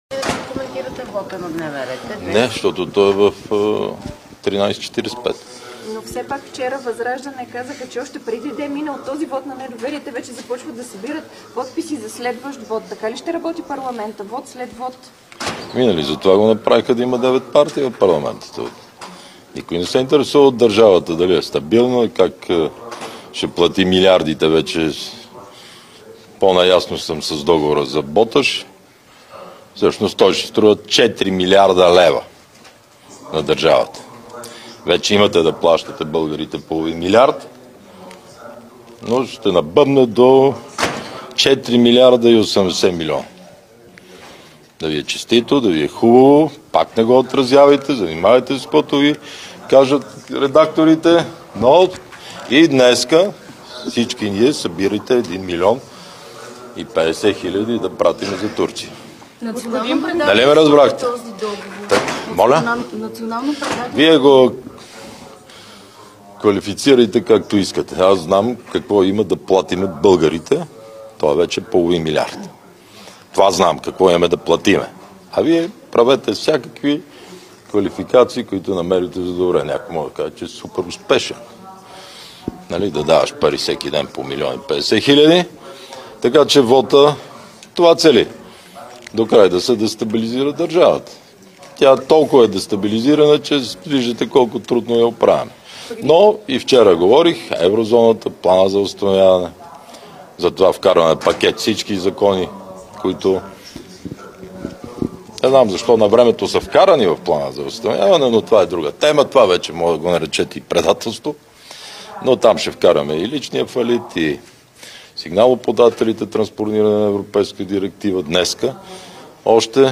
9.05 - Заседание на Народното събрание.
директно от мястото на събитието (Народното събрание)
Директно от мястото на събитието